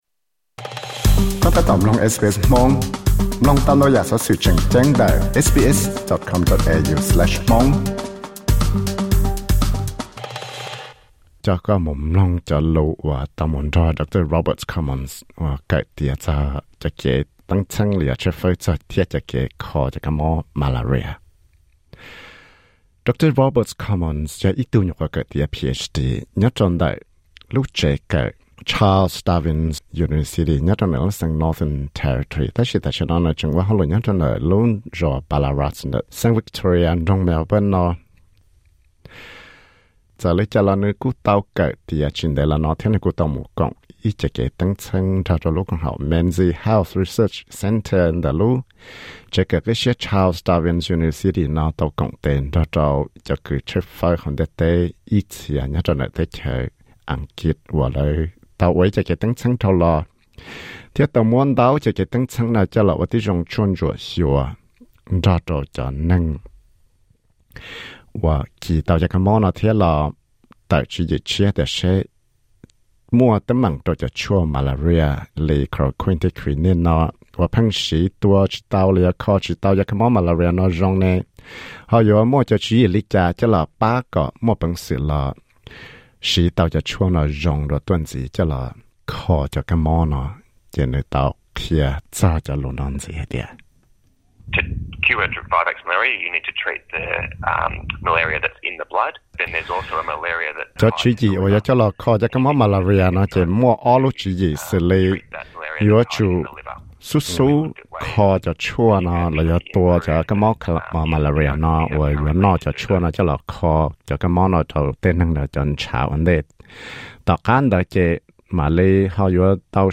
READ MORE Inaugural World Malaria conference held in Melbourne READ MORE Malaria research READ MORE Malaria award SKIP ADVERTISEMENT Disclaimer: This interview is intended for general information only.